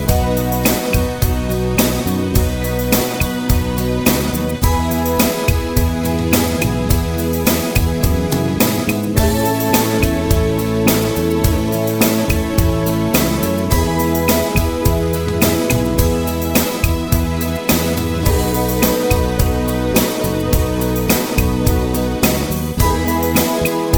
Two Semitones Up Pop (1960s) 3:58 Buy £1.50